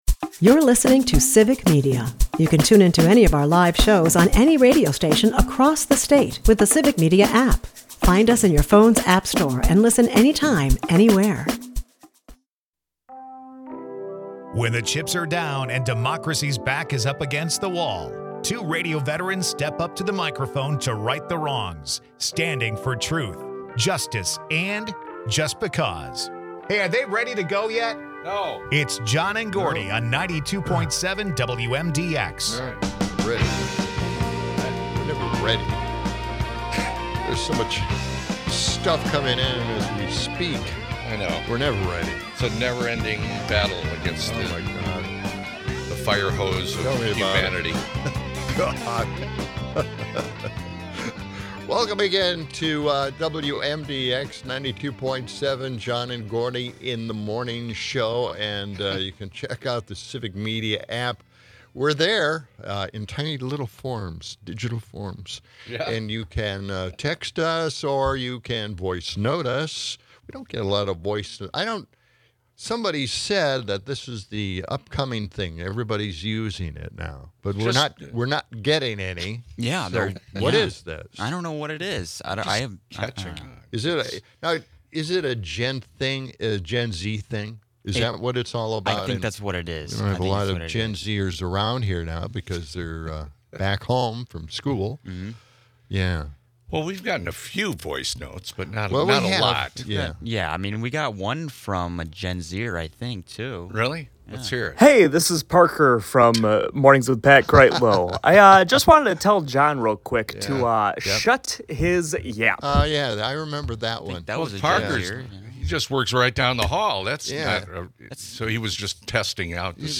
The duo humorously debates summer's sweltering weather and ponders the mysteries of nature's caterpillars.
They also share insights on the auto industry's shift back to gas guzzlers, while listeners weigh in on the complexities of modern cars. As they dive into the absurdities of bureaucratic redundancies, they keep the laughs coming with quirky 'Would You Rather' questions and hilarious discussions on the daily grind of radio life.